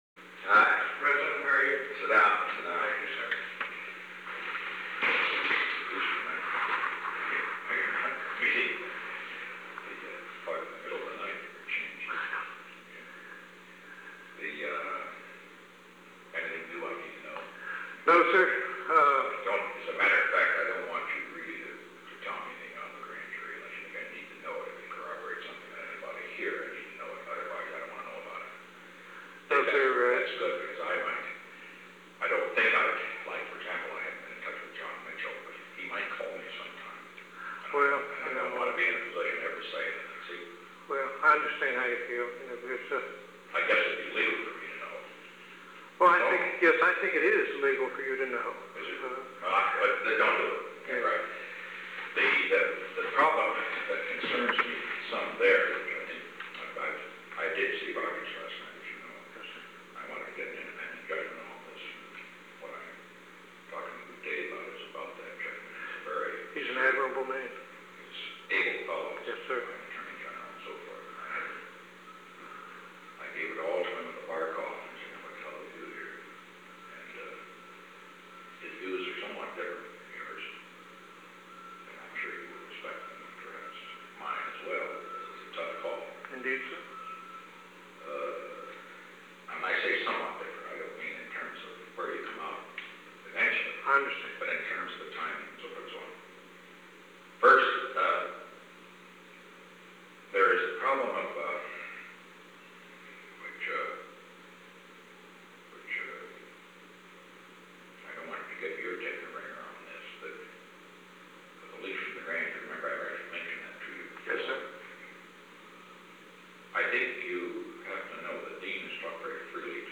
Conversation No. 898-21 Date: April 17, 1973 Time: 2:46-3:49 pm Location: Oval Office The President met with Henry E. Petersen.
Secret White House Tapes